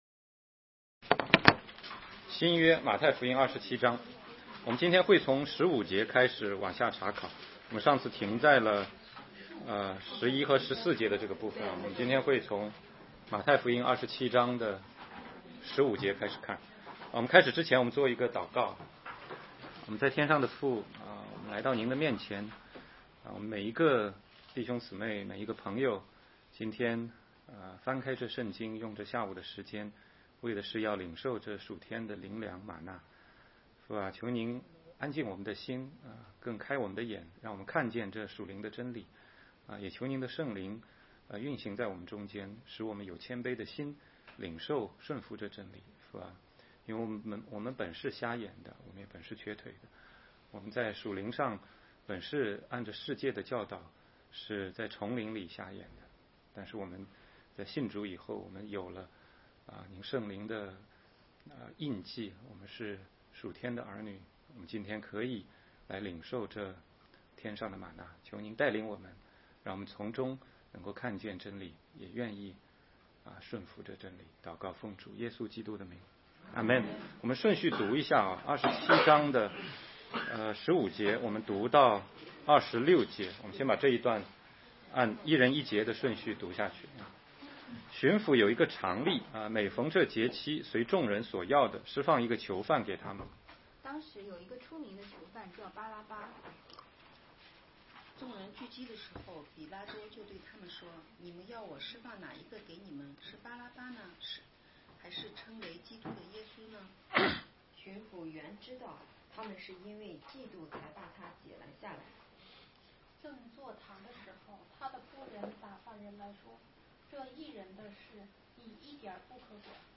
16街讲道录音 - 马太福音27章15-26节：关于巴拉巴和彼拉多